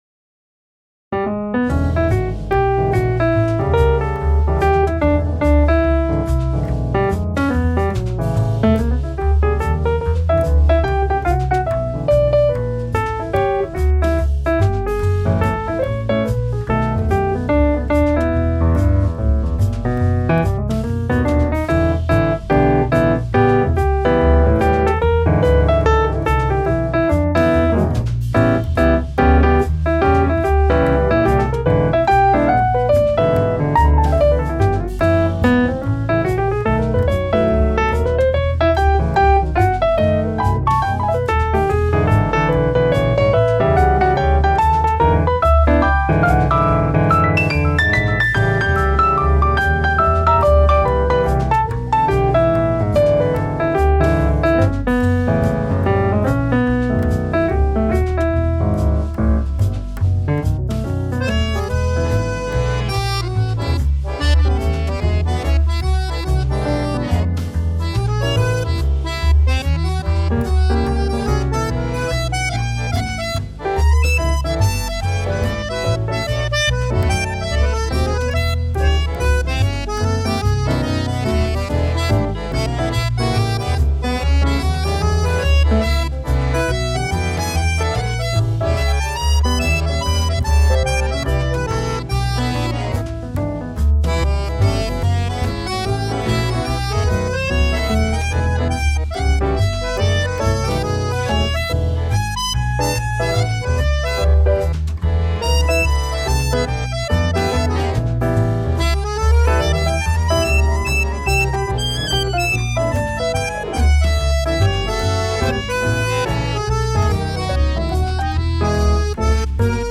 ピアノを弾きました 弾きたかったので...